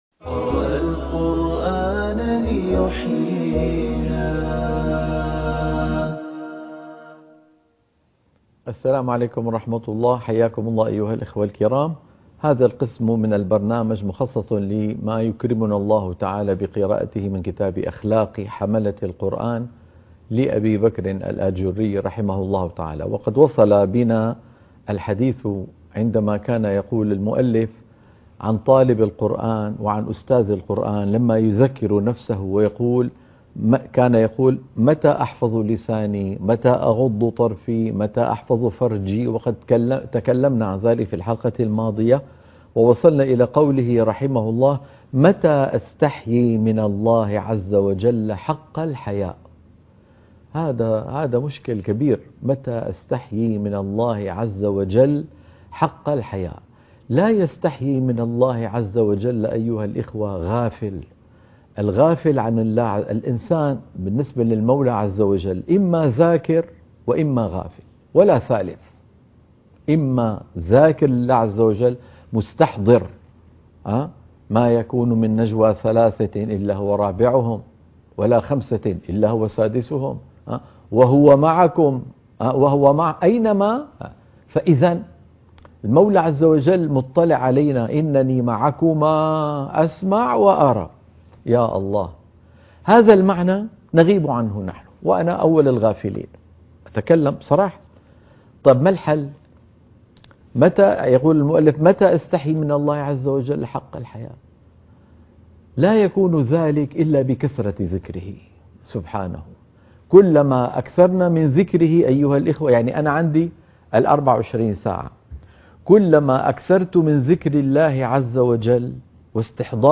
قراءة كتاب أخلاق حملة القرآن - الحلقة 25